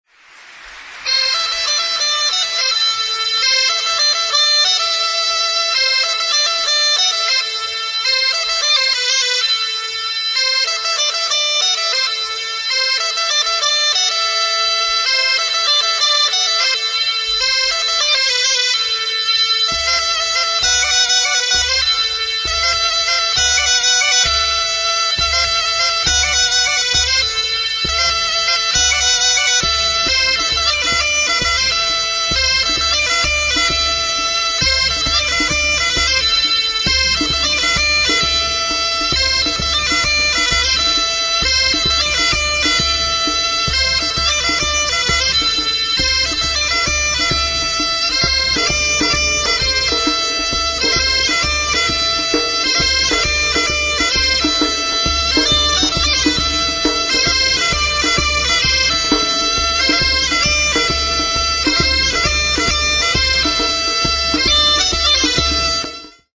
Usurbilgo kiroldegian. 2005-05-06.
ALBOKA
Aerófonos -> Lengüetas -> Simple (clarinete)
Klarinete bikoitza da, alboka arrunta.